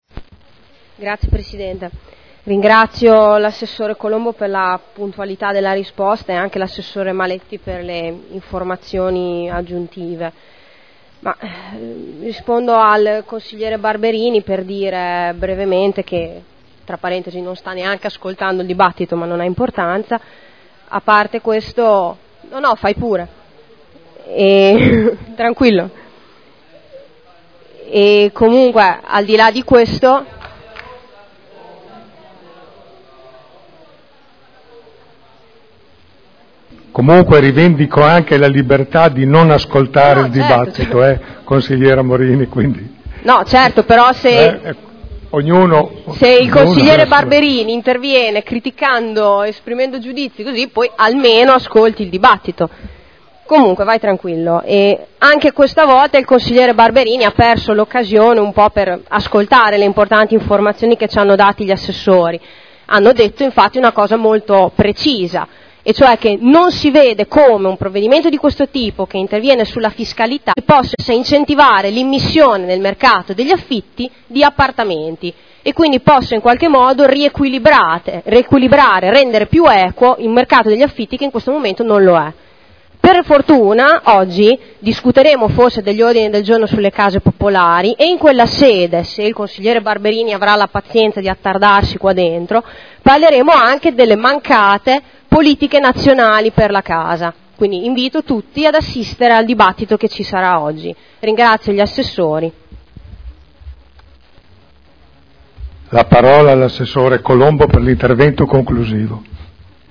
Seduta del 27/06/2011. Interrogazione delle consigliere Morini e Urbelli (P.D.) avente per oggetto: “Effetti della cd. “cedolare secca” sul mercato degli affitti” Replica